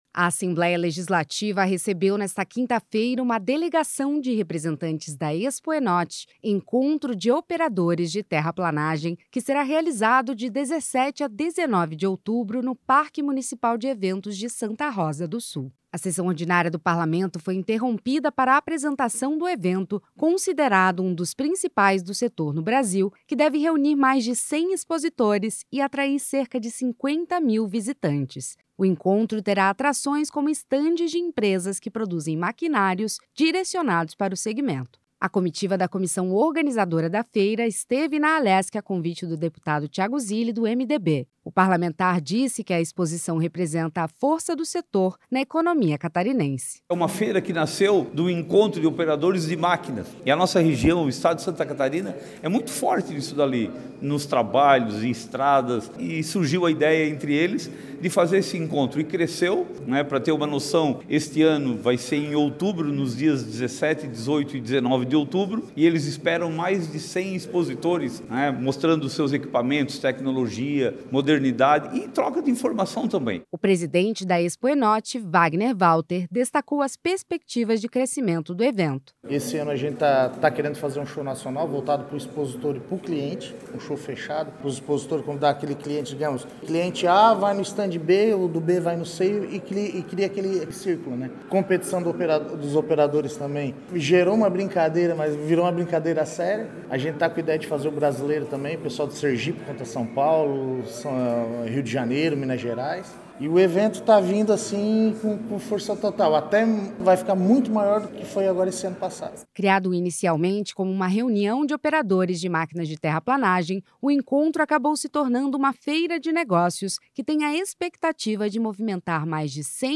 Entrevistas com:
- deputado Tiago Zilli (MDB), proponente da suspensão;
- deputado Mário Motta (PSD);